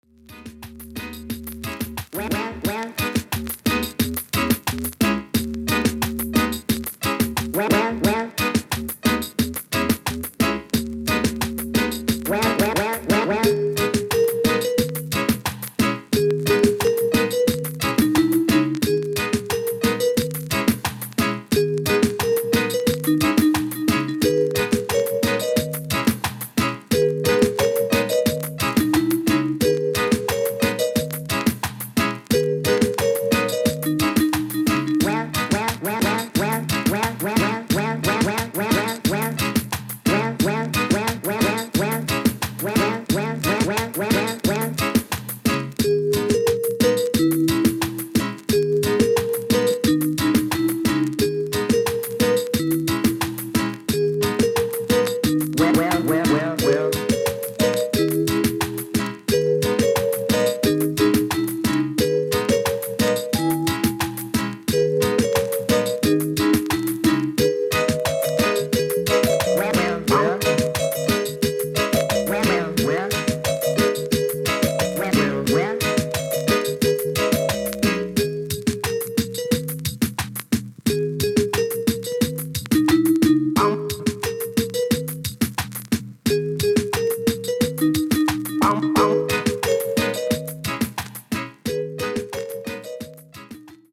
９０年代・KILLER DIGI CLASSIC!!!